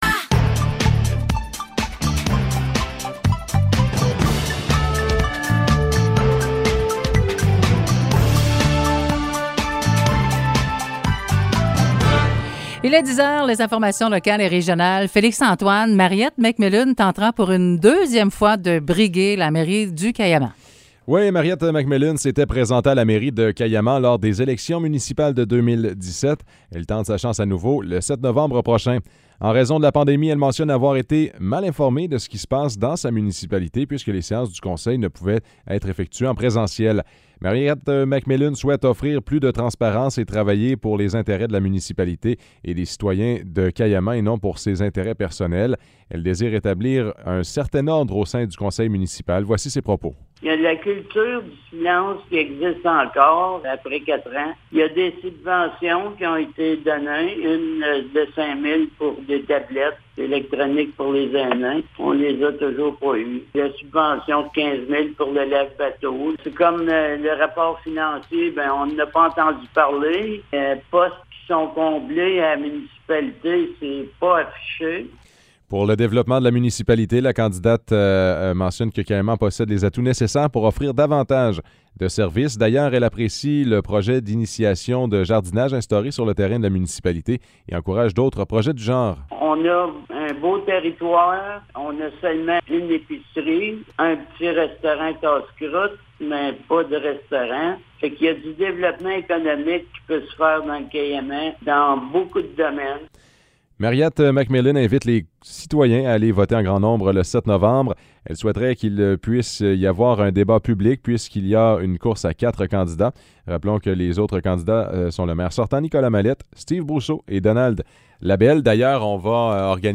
Nouvelles locales - 8 octobre 2021 - 10 h